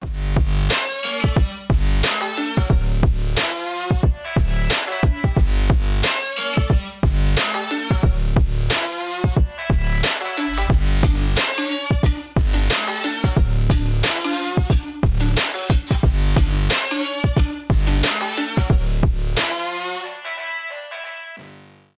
trap , electronic